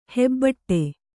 ♪ hebbaṭṭe